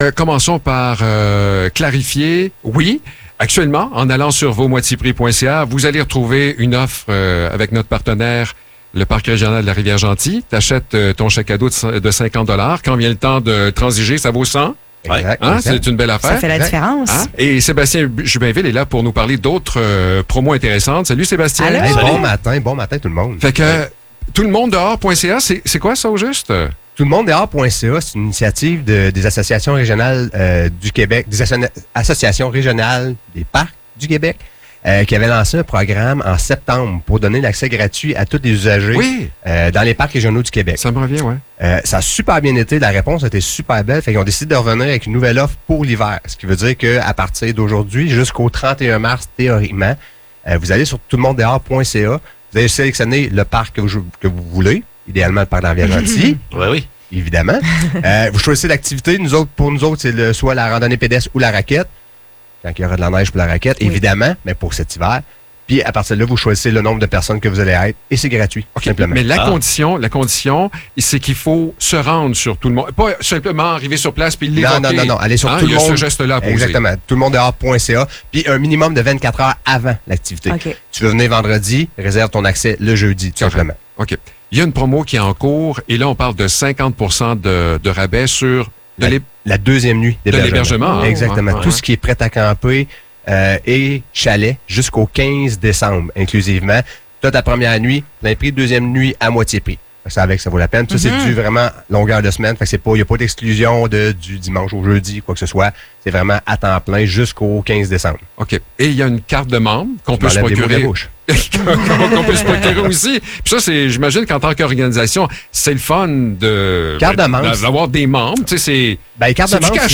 Entrevue avec le Parc de la rivière Gentilly